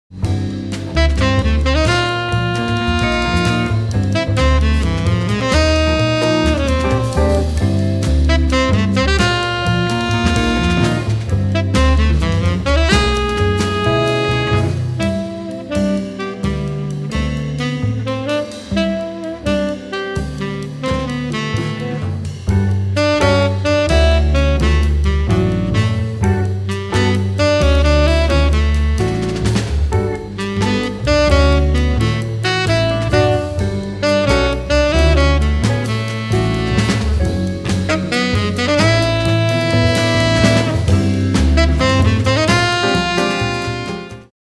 tenor saxophone
guitar
bass
drums